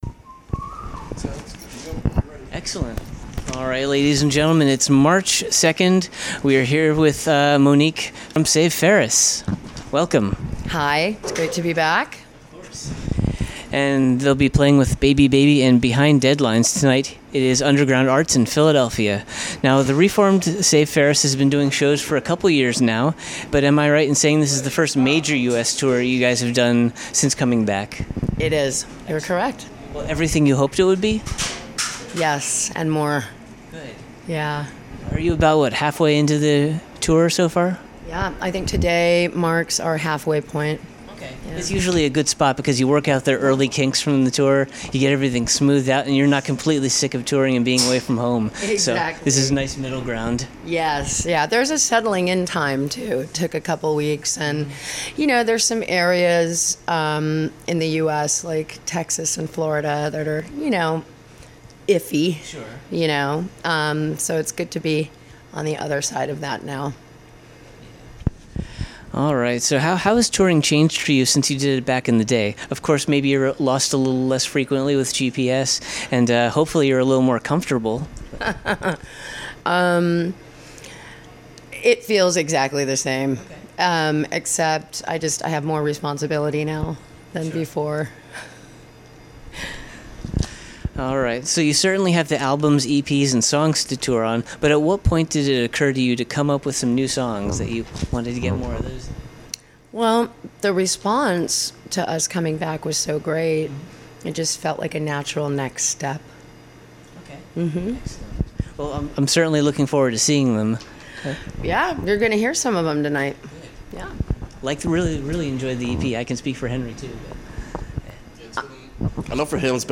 78-interview-save-ferris.mp3